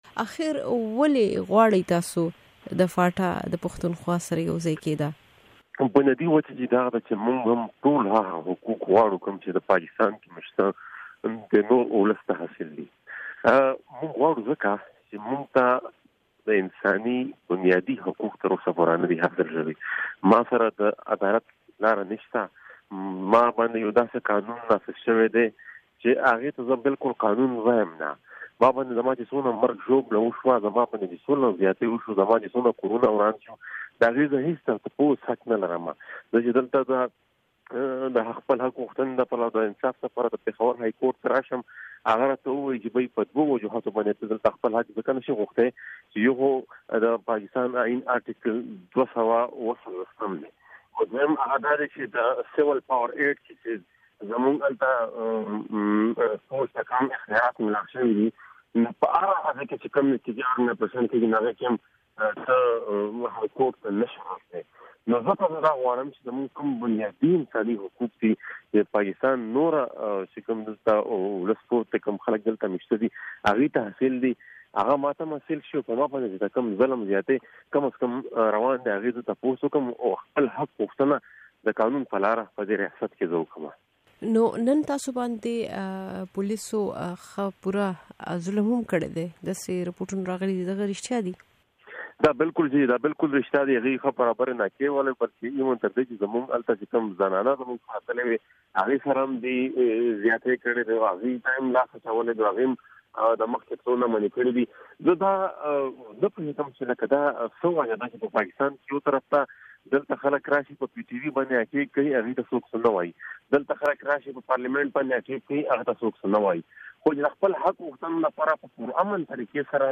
د محسن داوړ سره مرکه